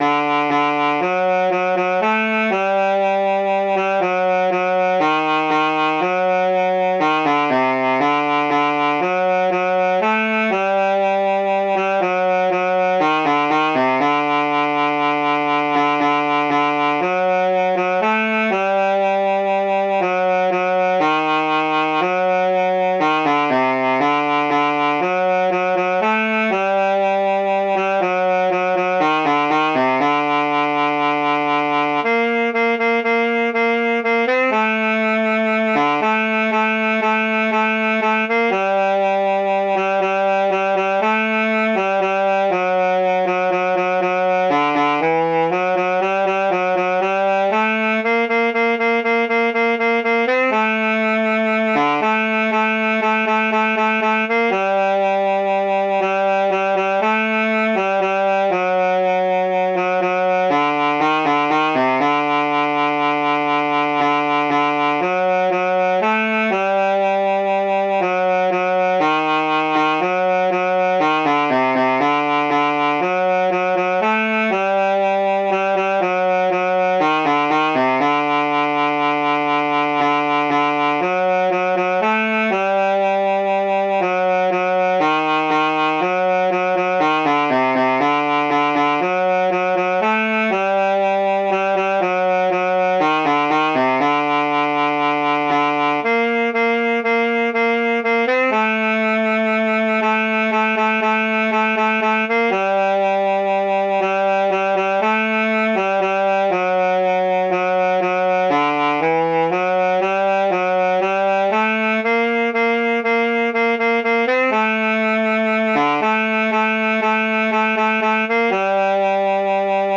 Jewish Folk Song
Shabbat
G minor ♩= 30 bpm